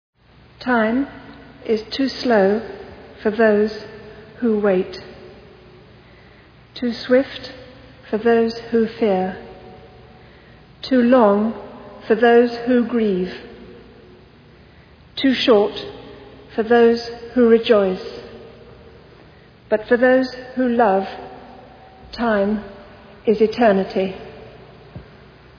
The Funeral of Diana, Princess of Wales
Westminster Abbey, September 6, 1997, 11:00 a.m. BST
hear "Time," read by Lady Jane Fellowes, Diana's sister
ladyjanefellowes.wav